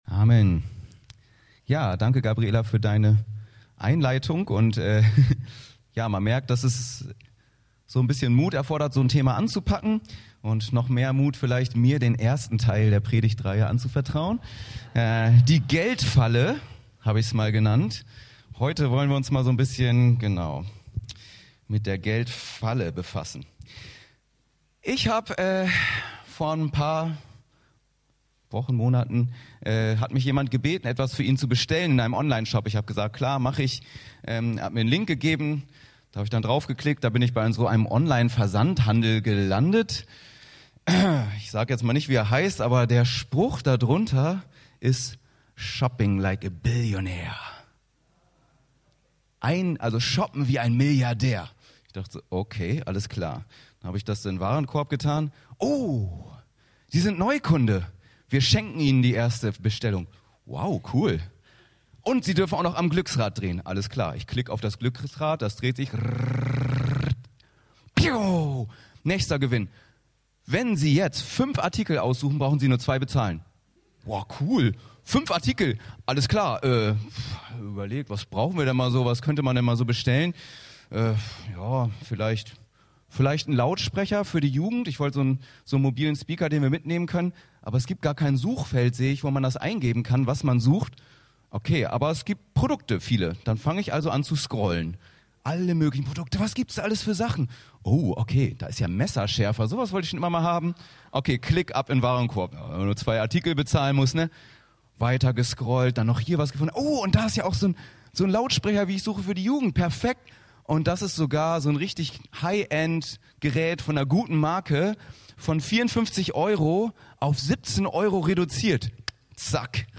Predigtreihe "KönigReich" 1: Die Geld Falle (1 Tim 6,5-11, Mt 6,19)